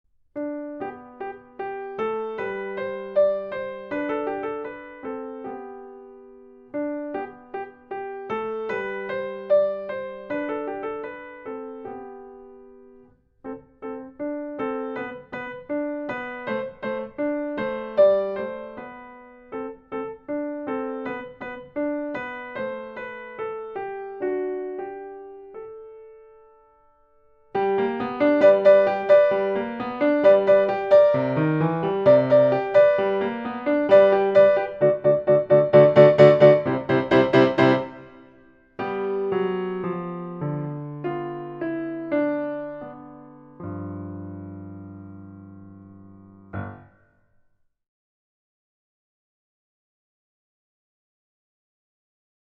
Recueil pour Piano